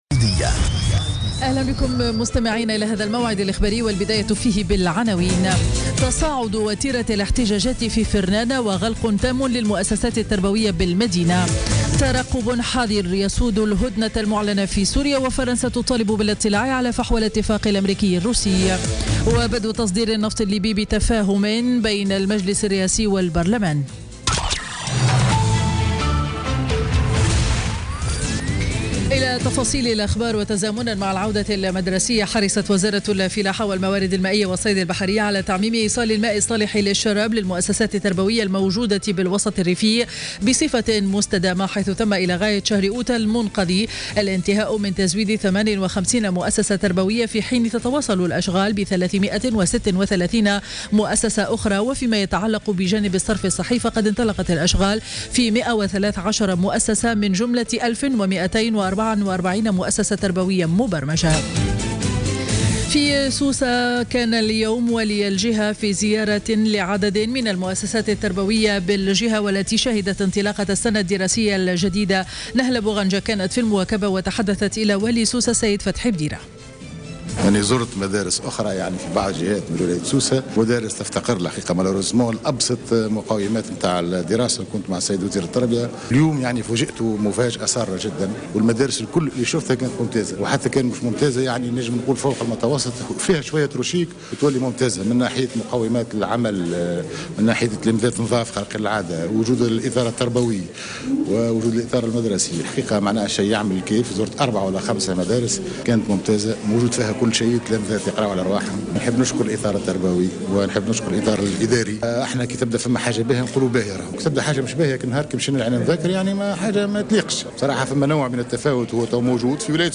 نشرة أخبار منتصف النهار ليوم الأربعاء 15 سبتمبر 2016